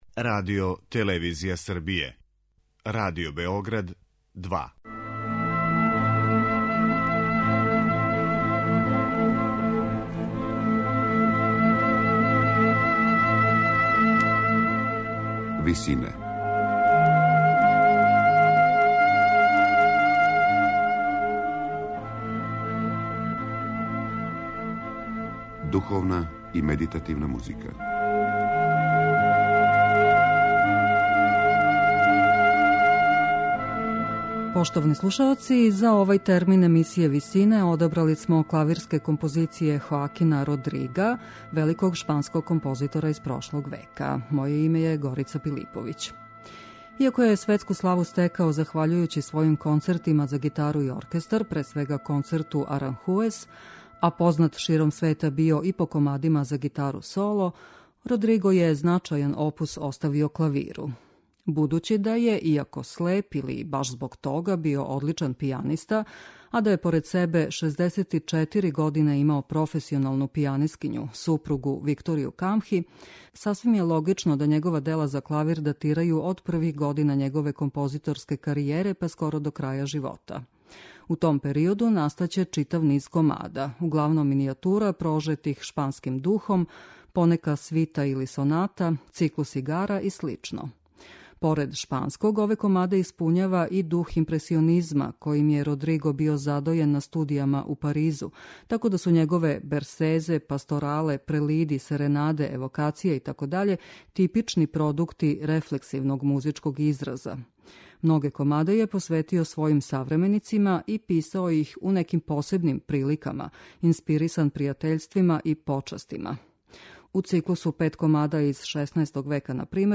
Хоакин Родриго: Комади за клавир